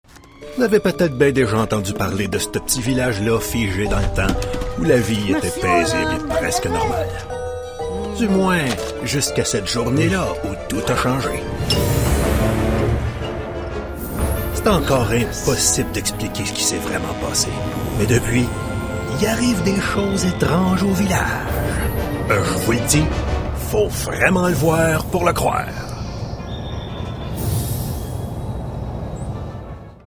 warm, authoritative French Canadian voice over with bass resonance
Movie Trailers
All this in a well-soundproofed cedar wardrobe that smells good!
Bass